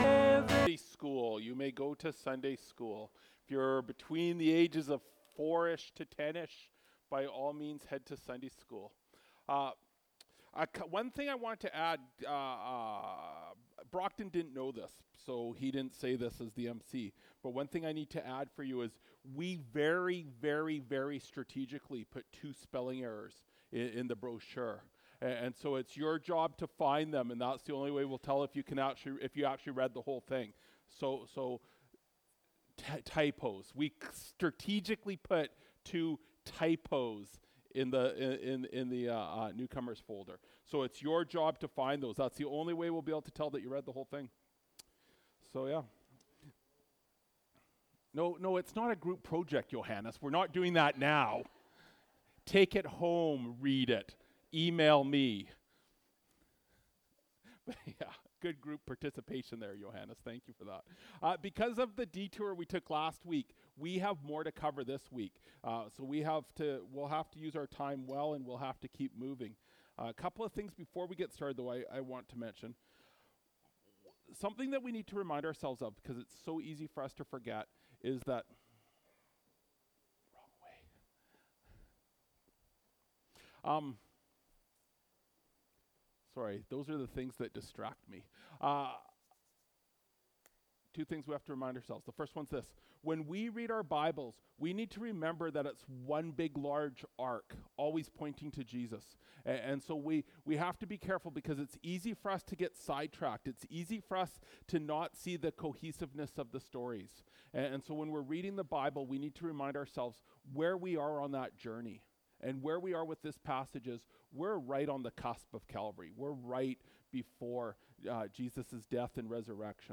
May 19, 2024 True Authority (John 18:1-27) MP3 SUBSCRIBE on iTunes(Podcast) Notes Discussion Sermons in this Series This sermon was recorded in Grace Church - Salmon Arm and preached in both campuses.